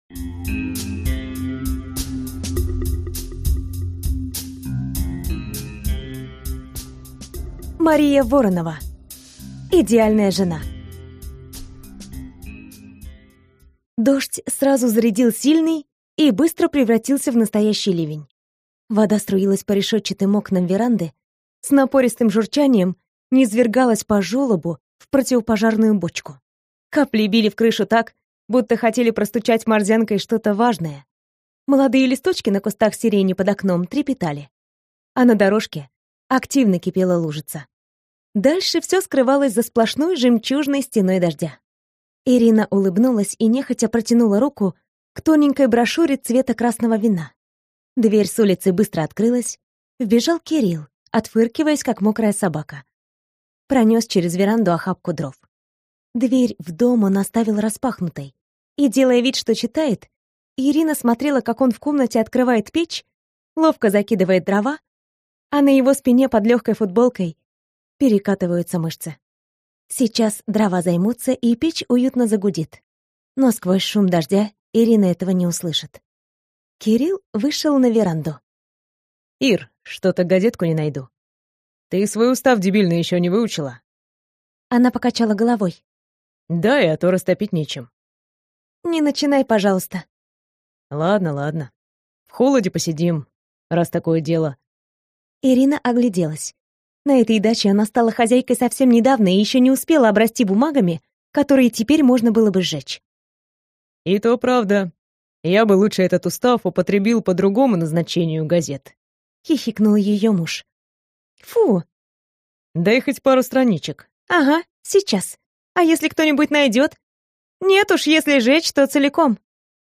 Аудиокнига Идеальная жена - купить, скачать и слушать онлайн | КнигоПоиск